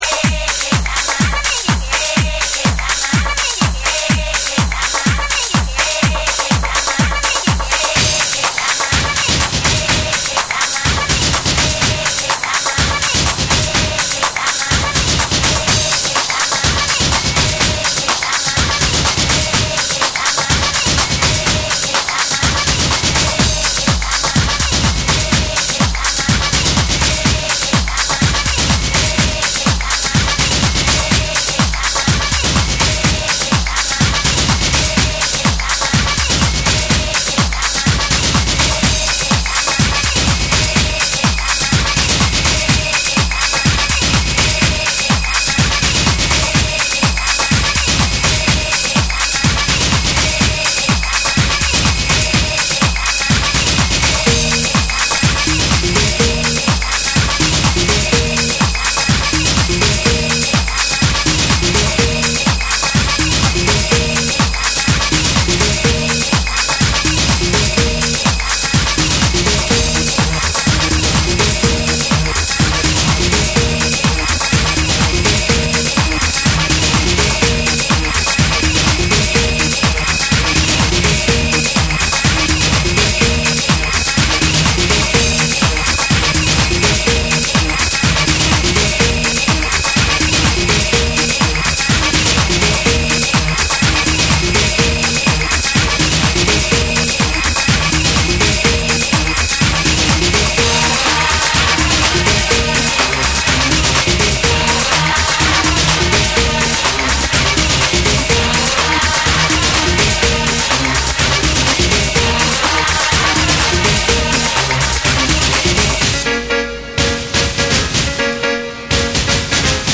TECHNO & HOUSE / BREAKBEATS / ORGANIC GROOVE